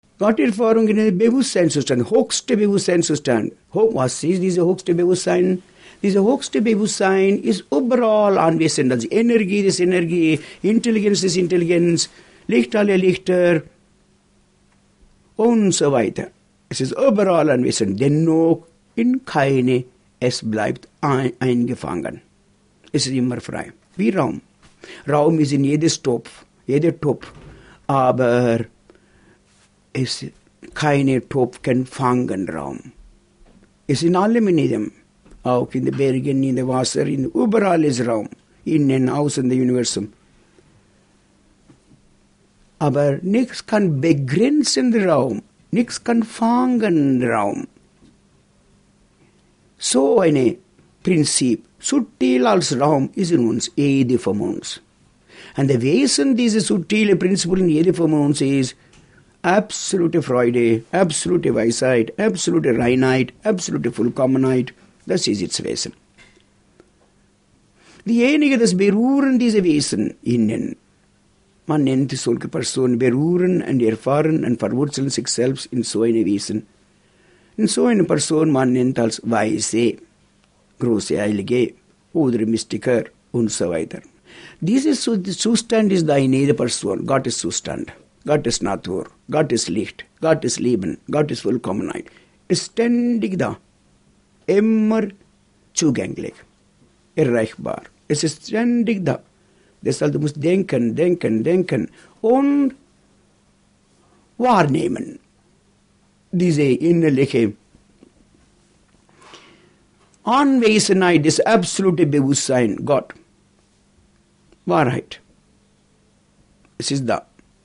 Vorträge
Vortrag